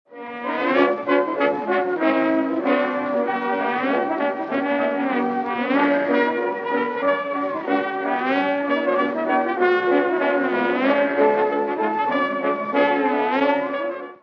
Trombone
trombone.mp3